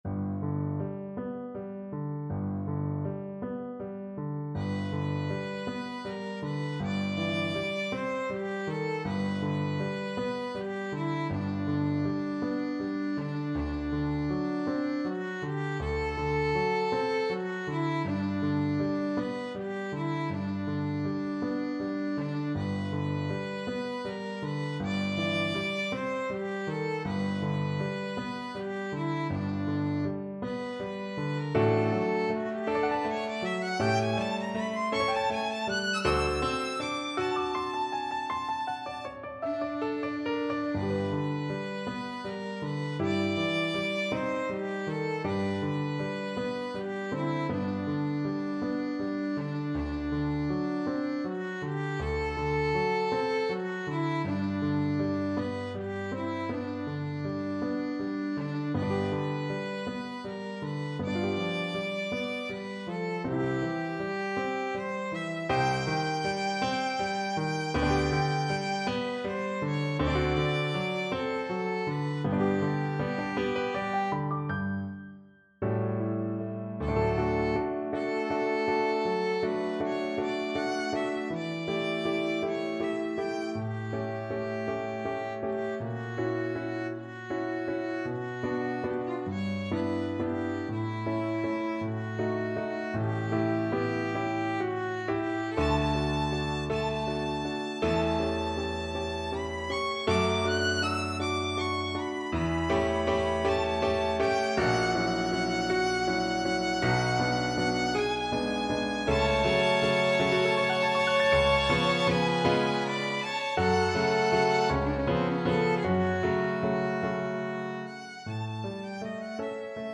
Violin
G major (Sounding Pitch) (View more G major Music for Violin )
~ = 100 Andante espressivo
12/8 (View more 12/8 Music)
G4-G7
Classical (View more Classical Violin Music)